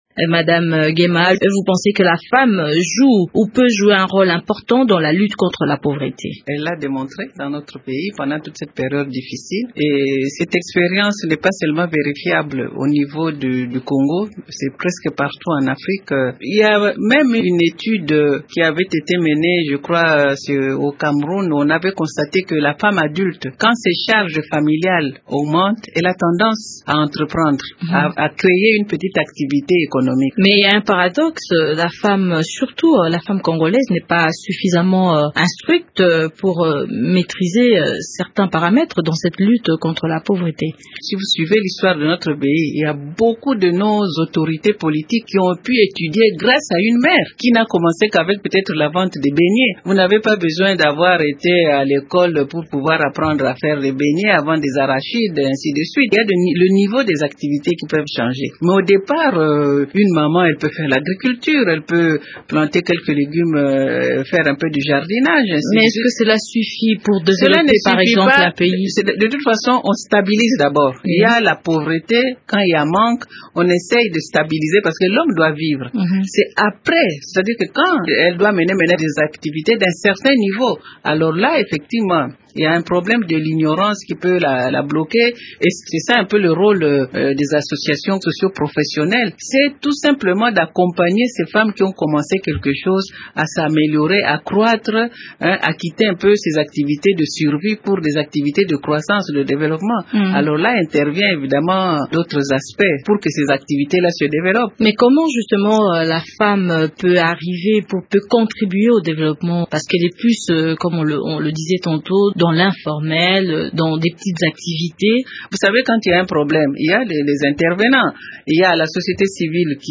A l’occasion de la journée internationale de la femme, elle a livré une interview à Radio Okapi.